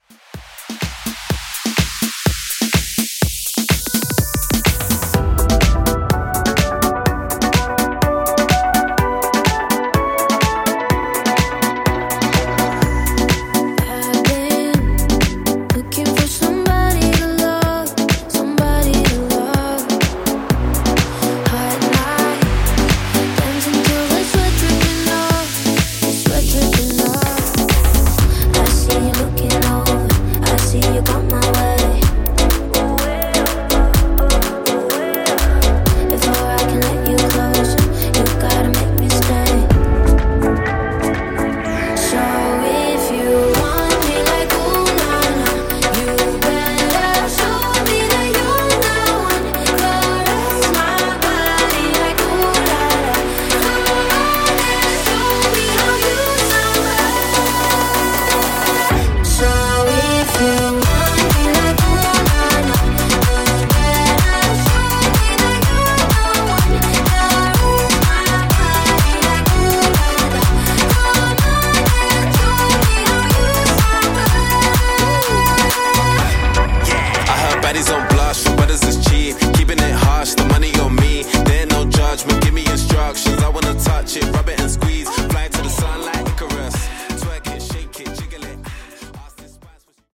Moombah Mixshow)Date Added